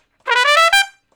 084 Trump Shuffle (E) 04.wav